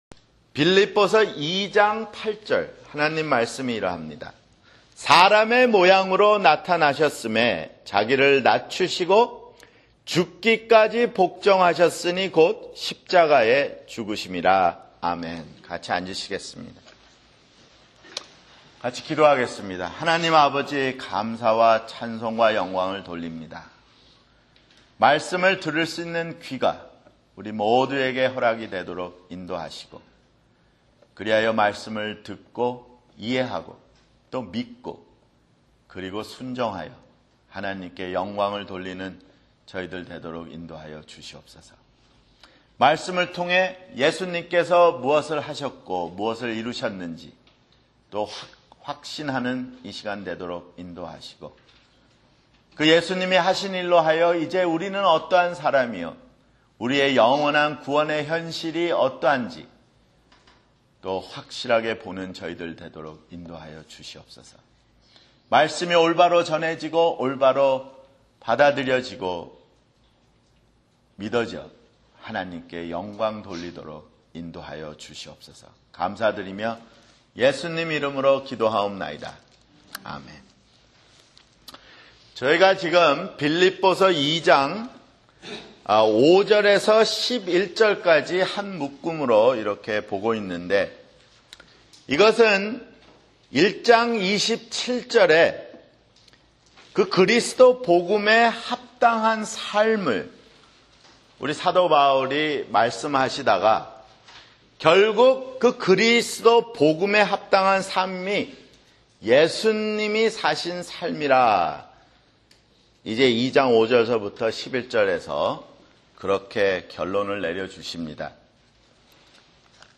[주일설교] 빌립보서 (26)